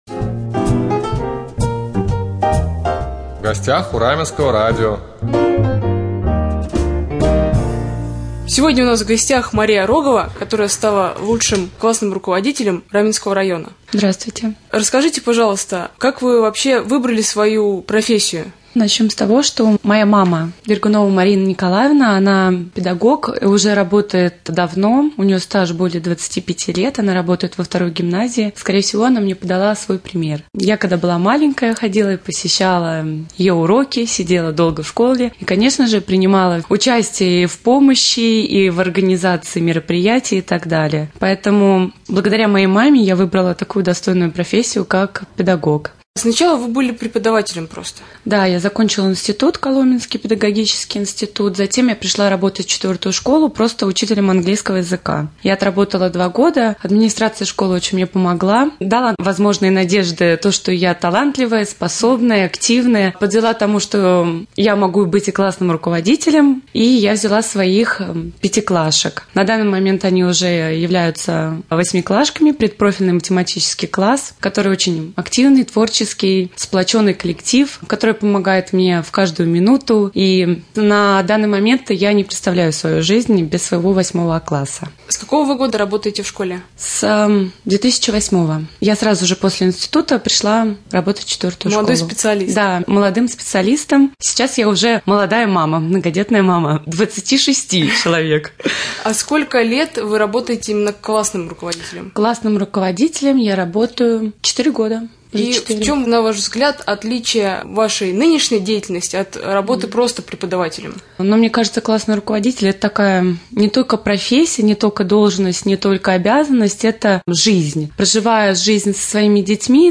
3.Рубрика «В гостях у Раменского радио». Гость студии